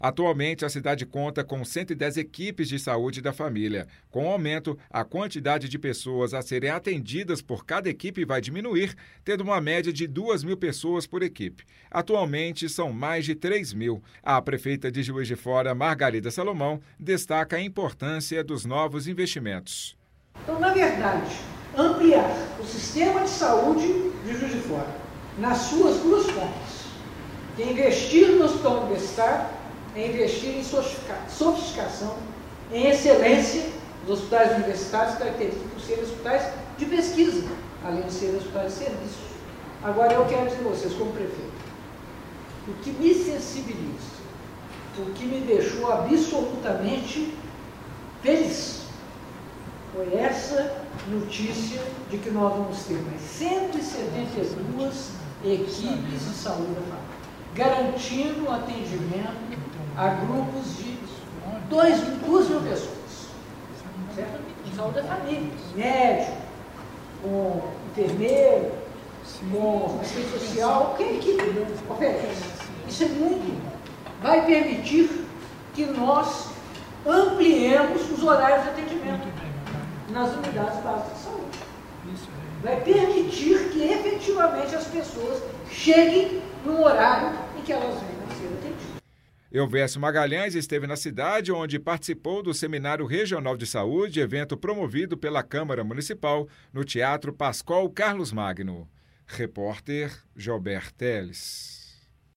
A prefeita de Juiz de Fora, Margarida Salomão, destaca a importância dos novos investimentos.